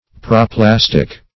Proplastic \Pro*plas"tic\, a. Forming a mold.